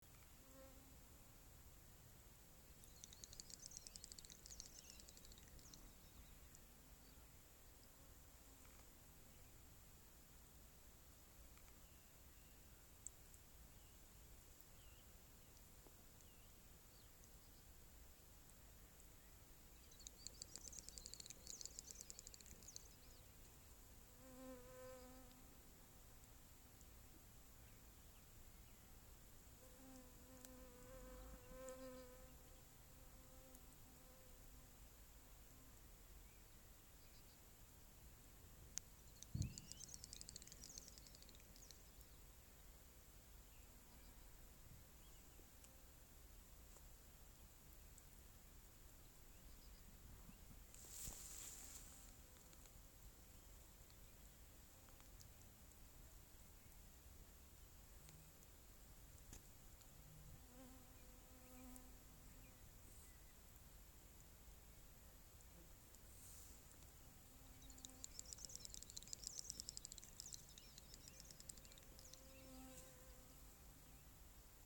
Great Snipe, Gallinago media
Count3
StatusSinging male in breeding season
Notes Šeit vismaz 3, pec vairākām dienām vēl pāris netālu dziedāja.